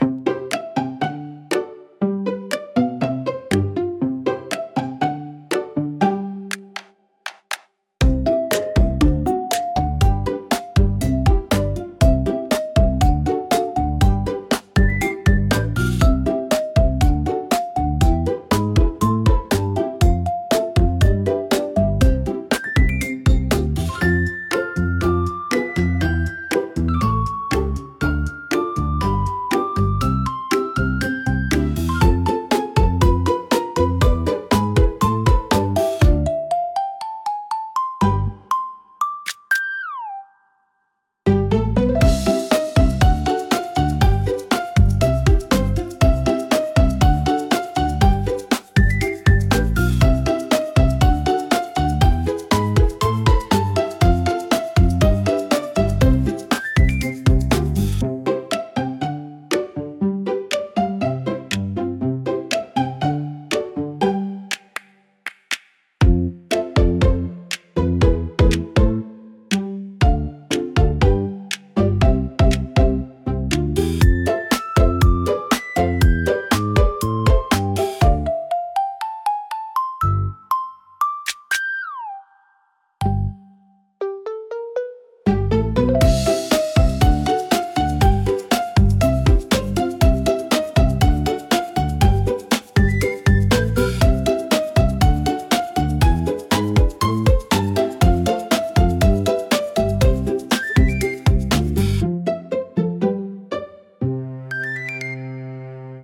軽やかな弦のはじき音が繊細に響き、優しく愛らしい雰囲気を作り出します。
親しみやすくやさしい印象を与えるジャンルです。